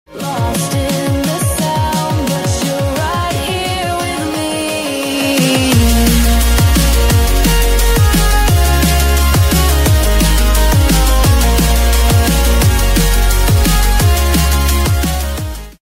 This edit hits hard with original music and intense anime moments.